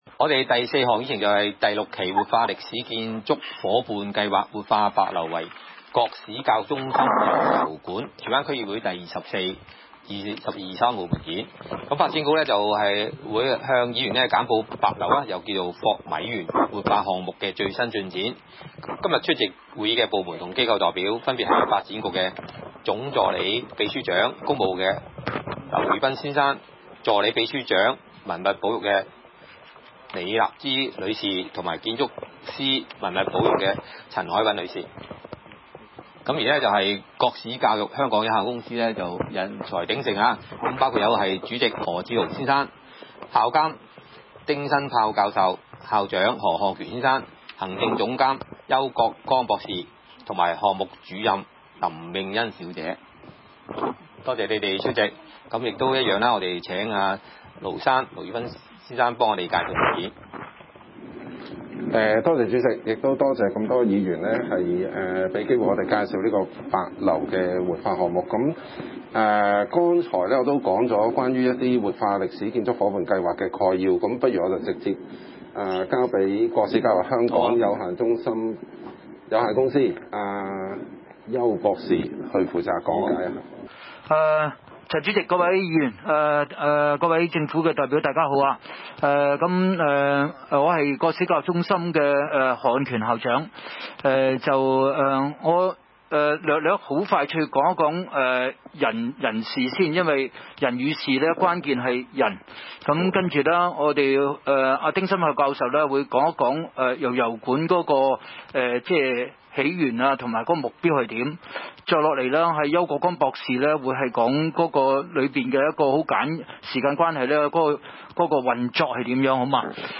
區議會大會的錄音記錄